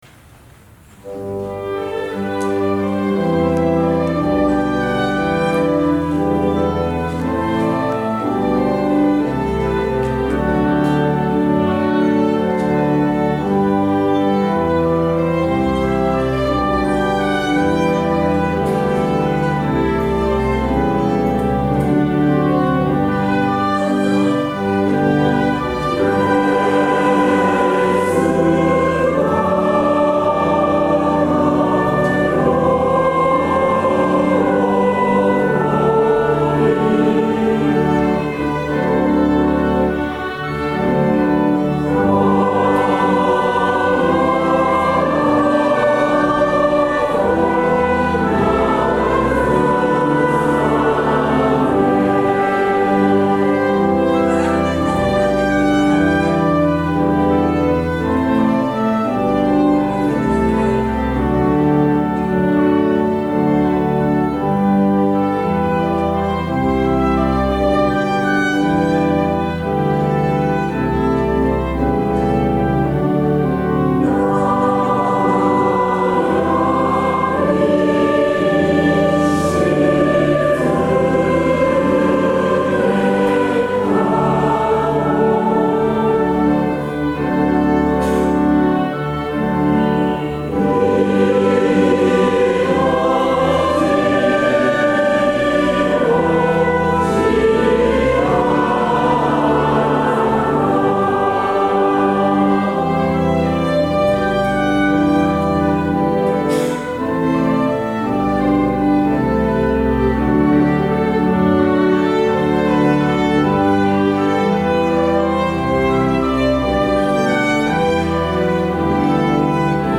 B.C.T.
at Misakicho Church